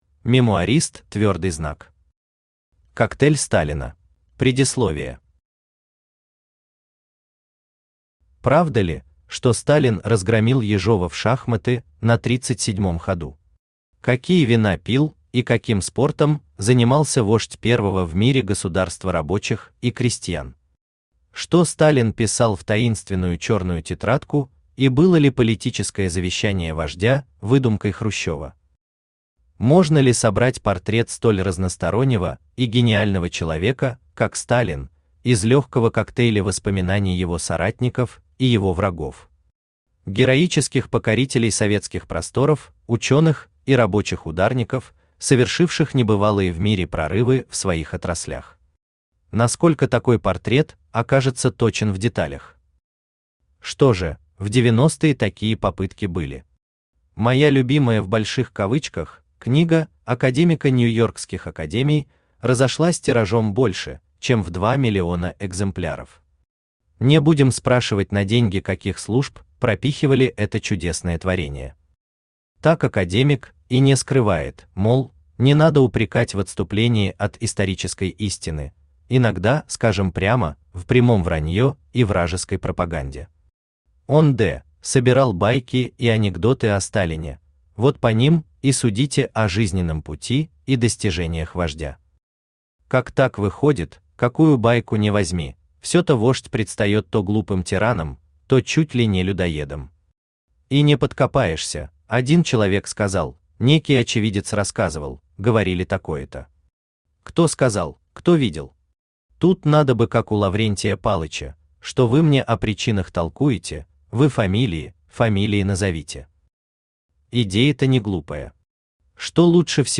Аудиокнига Коктейль Сталина | Библиотека аудиокниг
Aудиокнига Коктейль Сталина Автор МемуаристЪ Читает аудиокнигу Авточтец ЛитРес.